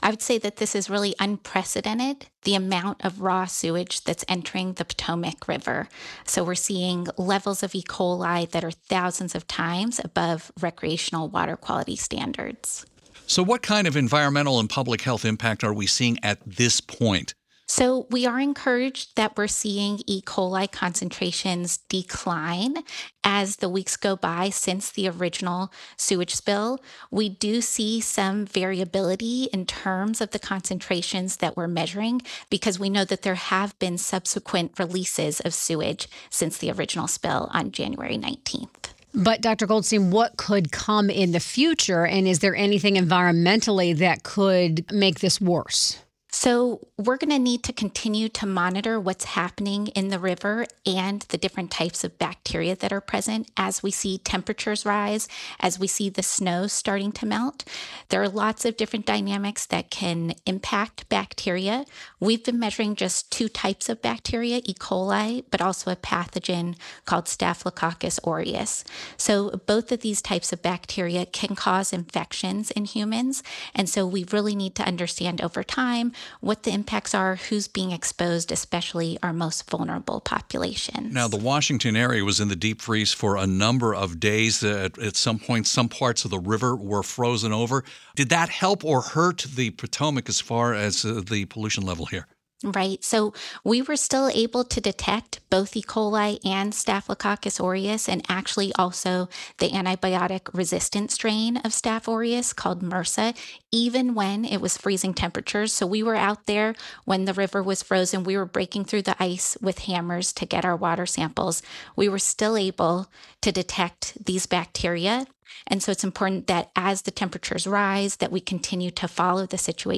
joined WTOP to talk about the spill.